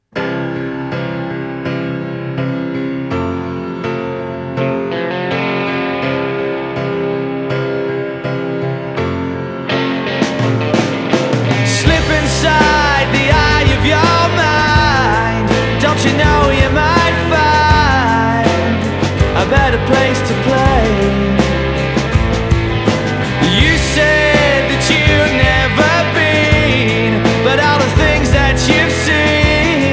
• Indie Rock